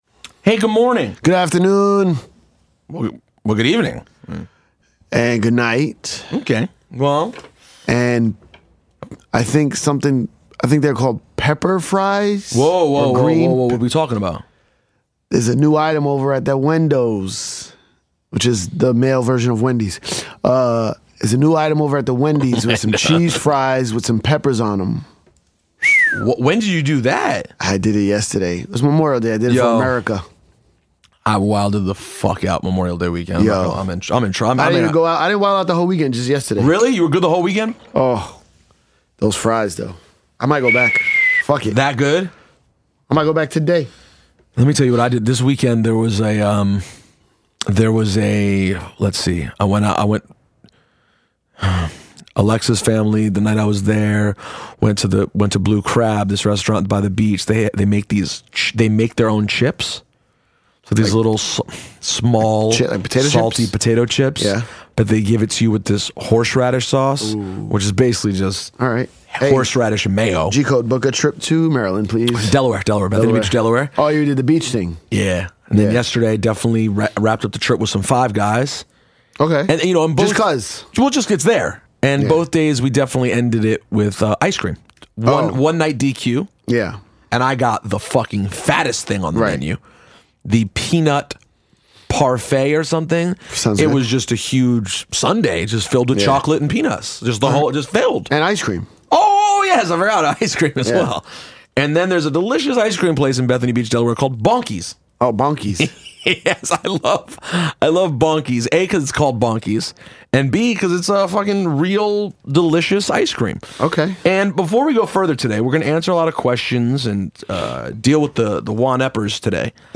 70 minutes of Ciph and I taking questions from all of our Patreon supporters!